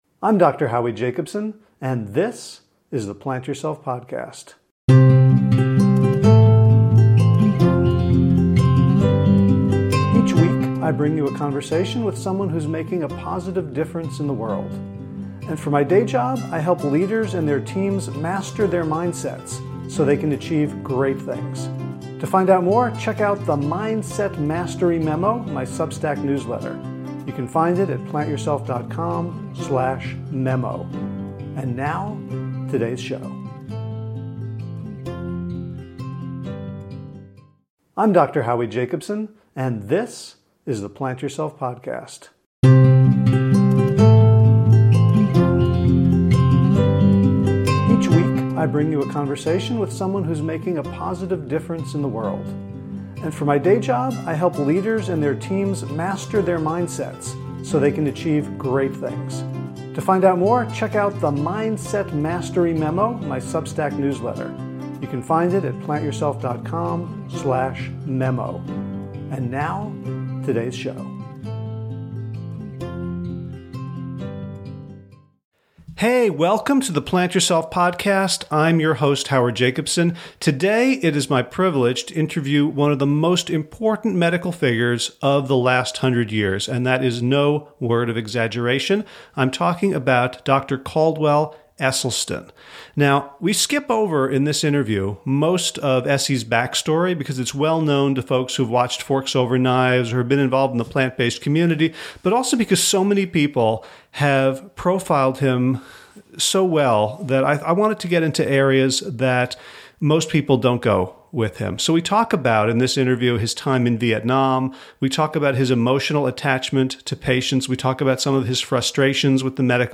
I'm delighted to share my interview with one of the most important medical figures of the 20th and 21st centuries.